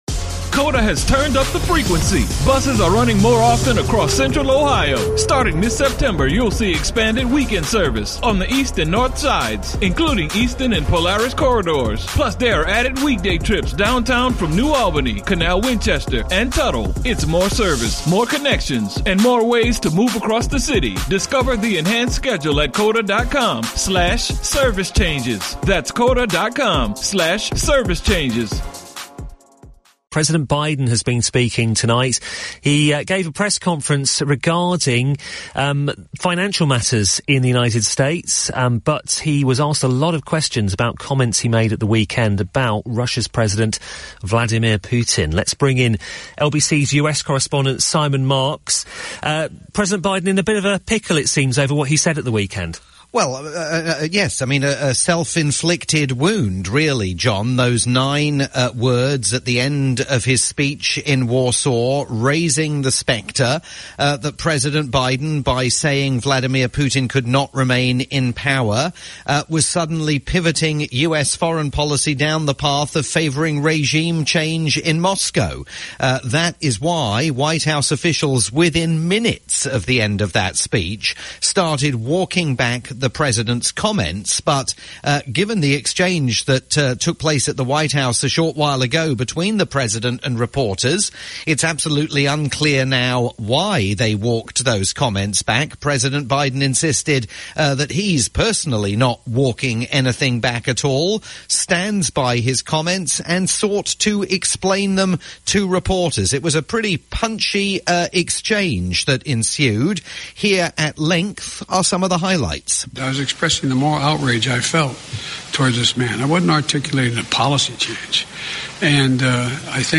live report for LBC News